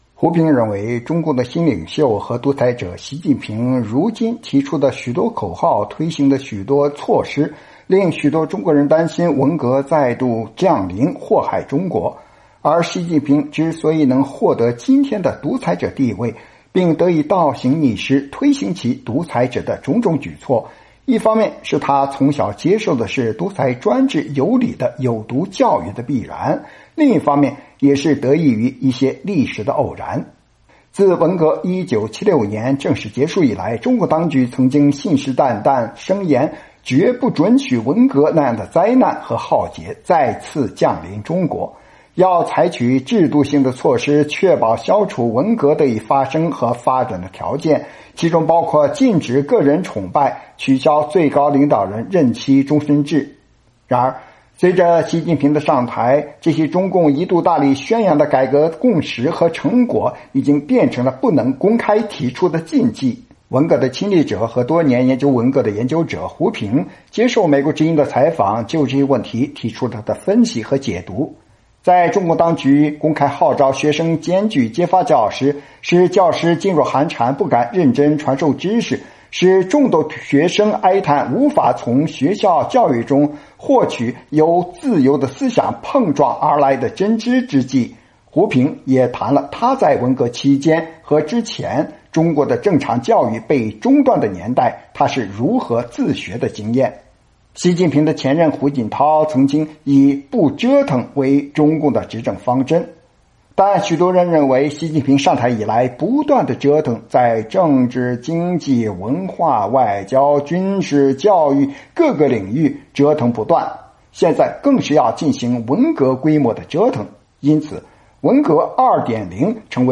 专访学者胡平(2)：谈文革研究与文革经验